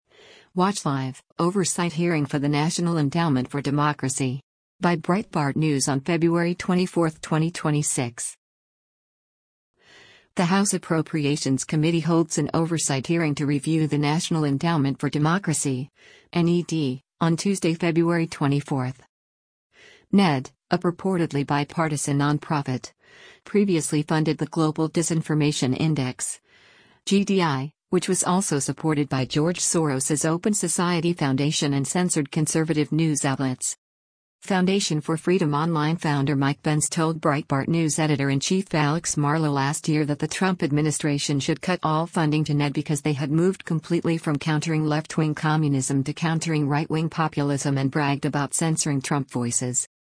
The House Appropriations committee holds an oversight hearing to review the National Endowment for Democracy (NED) on Tuesday, February 24.